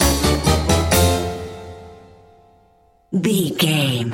Aeolian/Minor
orchestra
percussion
silly
circus
goofy
comical
cheerful
perky
Light hearted
quirky